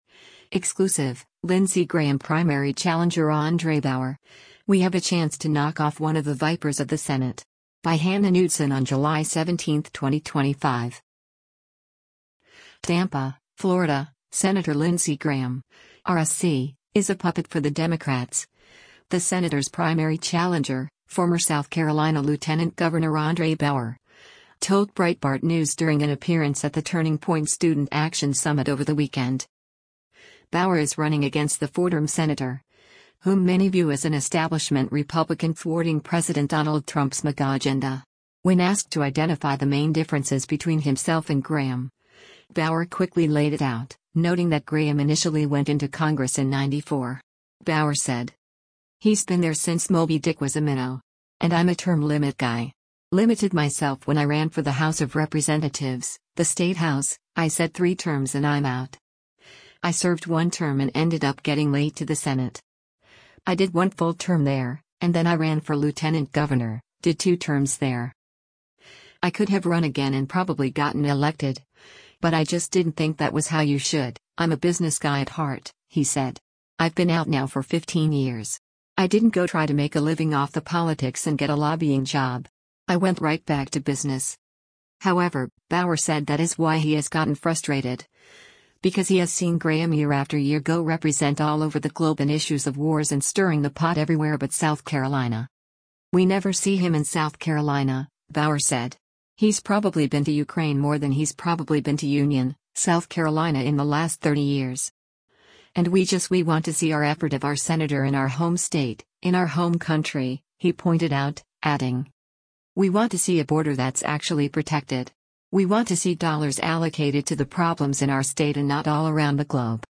TAMPA, Florida — Sen. Lindsey Graham (R-SC) is a “puppet for the Democrats,” the senator’s primary challenger, former South Carolina Lt. Gov. André Bauer, told Breitbart News during an appearance at the Turning Point Student Action Summit over the weekend.